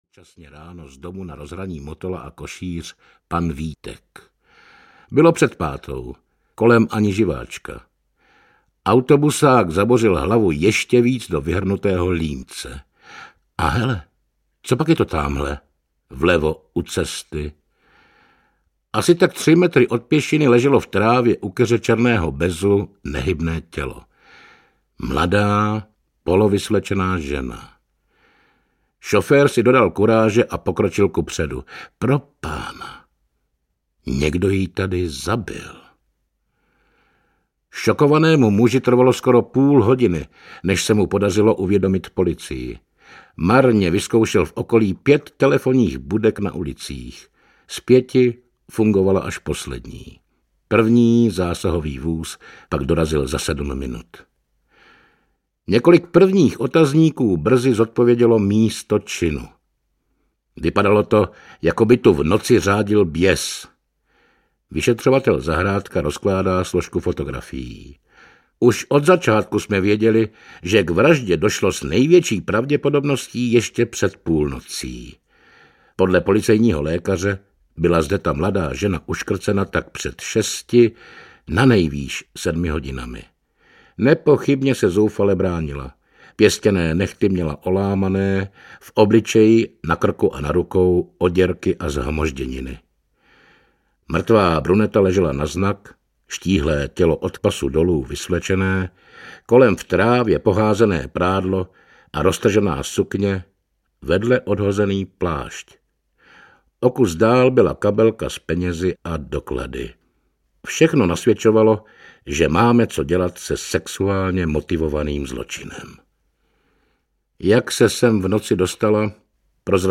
Audiokniha Staré vraždy (10 československých kriminálních příběhů), kterou napsal I. M. Jedlička. Deset kriminálních příběhů podle skutečných událostí čte herec Norbert Lichý.
Ukázka z knihy
• InterpretNorbert Lichý